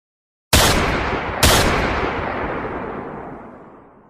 Realistic Double Gunshot Sound Effect: Intense Action & Impact
Experience the sharp, impactful sound of two rapid gunshots, perfect for adding drama and realism to your projects.
realistic-double-gunshot-sound-effect-intense-action-impact-bcff93f7.mp3